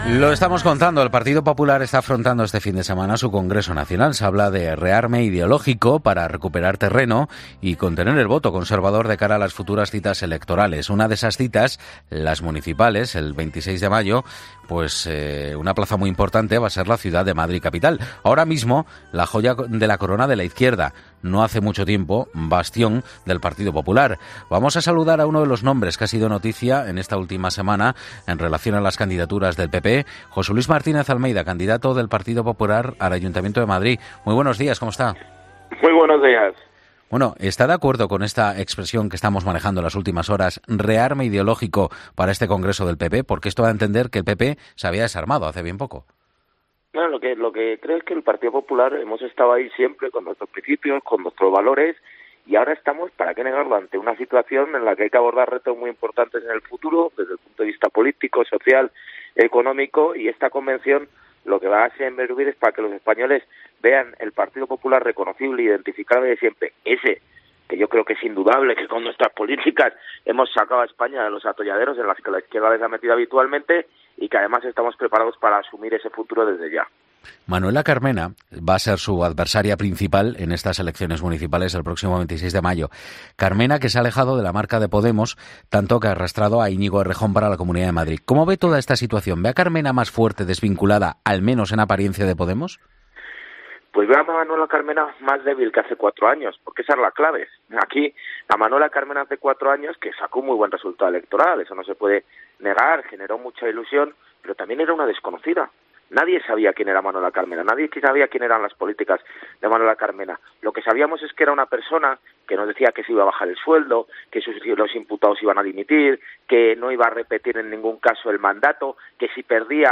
José Luis Martínez-Almeida, candidato del PP a la alcaldía de Madrid, en 'La Mañana Fin de Semana'